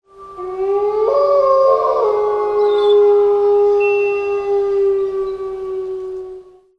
wolf-howl-1.ogg